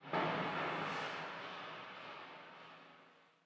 ambienturban_11.ogg